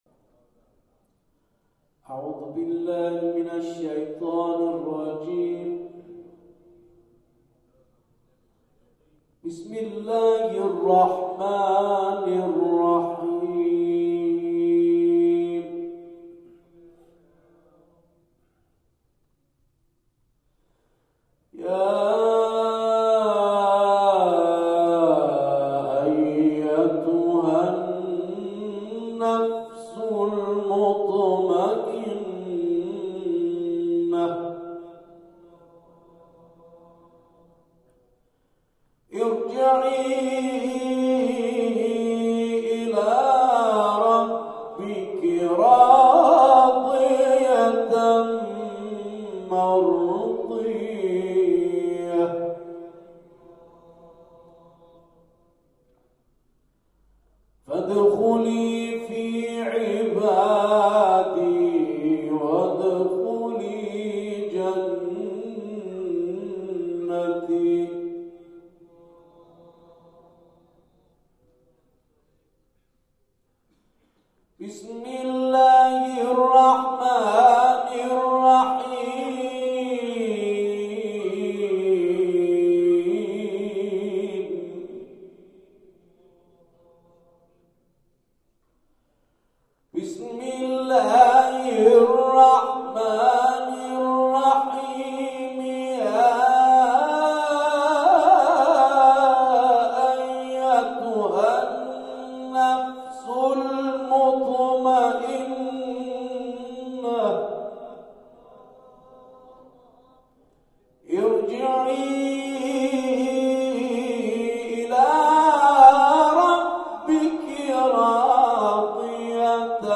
گروه جلسات و محافل: محفل انس با قرآن کریم، شب گذشته 13 آبان ماه، در امامزاده جعفر بن موسی الکاظم(ع) پیشوا برگزار شد.
سپس سوره حمد و اخلاص را همخوانی کرد و در ادامه، آیاتی از سوره مبارکه فجر و اعلی و کوثر را تلاوت کرد.
در ادامه تلاوت های این محفل ارائه می‌شود.